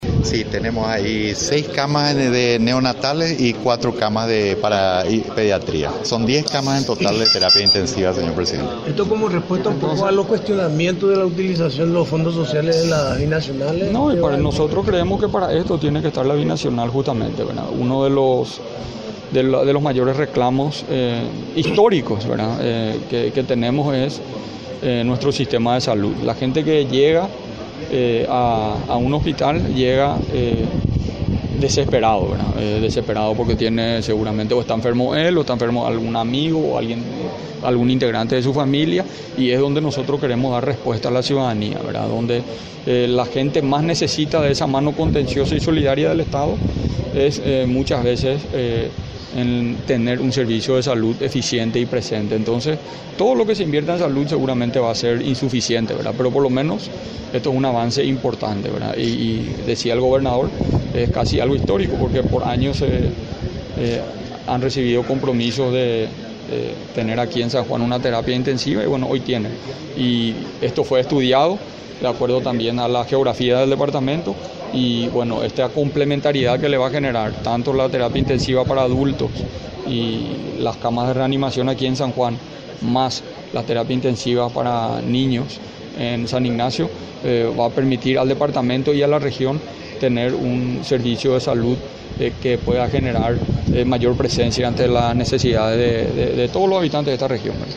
El Gobierno se enfocará en el sector de la salud pública, con la finalidad de mejorar la calidad y la atención para todos los compatriotas, aseguró el presidente de la República Mario Abdo Benítez, durante el acto en San Ignacio Misiones, ocasión en que se procedió a la inauguración de una unidad de terapia intensiva pediátrica.